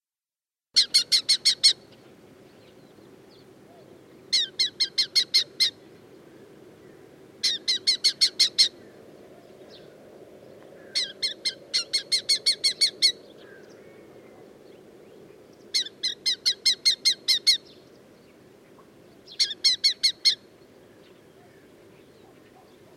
Вы услышите разнообразные голосовые вариации: от характерных резких криков до более мягких перекличек. Записи сделаны в дикой природе разных регионов, что позволяет оценить особенности вокализации птицы.
Звук кобчика в Румынии